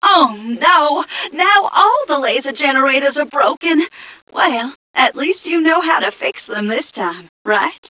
mission_voice_m2ca009.wav